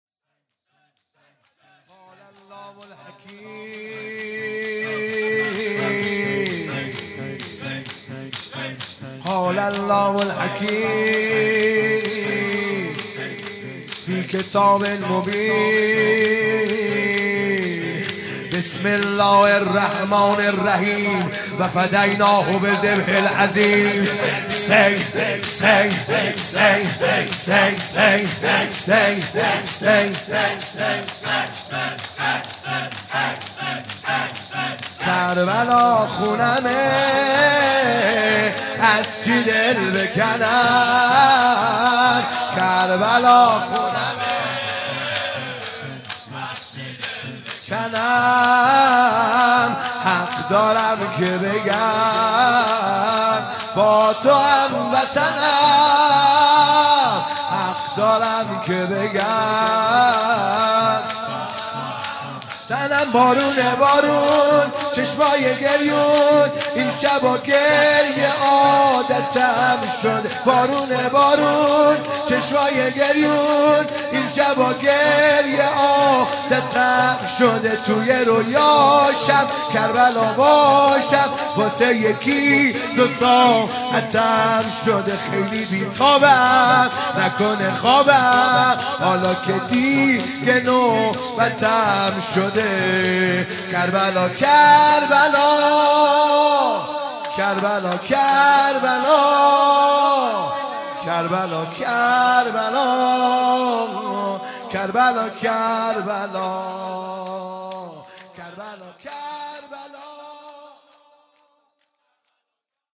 شور
شهادت امام رضا (ع) | ۹ آذر ۱۳۹۵